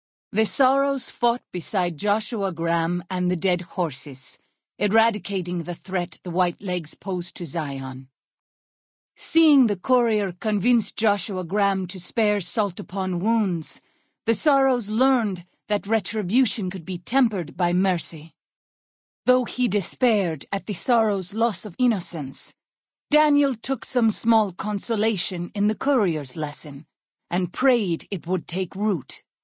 Category:Honest Hearts endgame narrations Du kannst diese Datei nicht überschreiben. Dateiverwendung Die folgende Seite verwendet diese Datei: Enden (Honest Hearts) Metadaten Diese Datei enthält weitere Informationen, die in der Regel von der Digitalkamera oder dem verwendeten Scanner stammen.